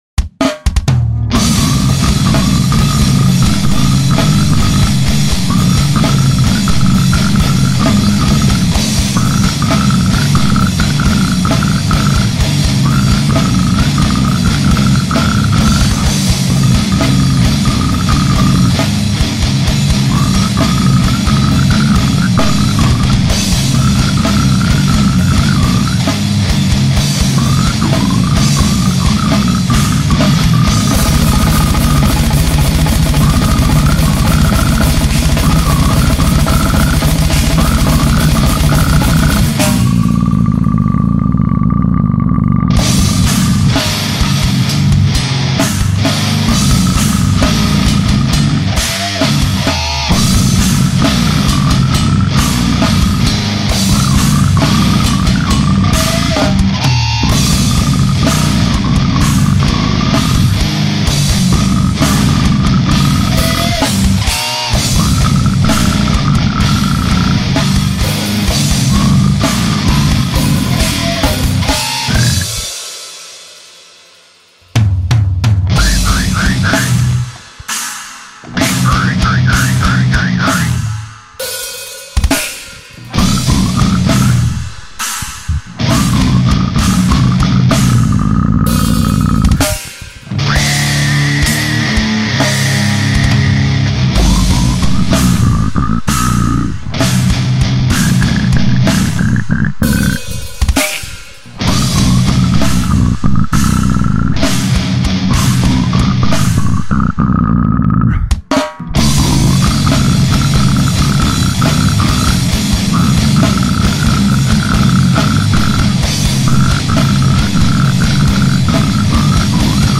Enjoy the track and it's signature GuttuRAWLS!